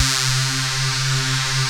JUNOPULSE3-L.wav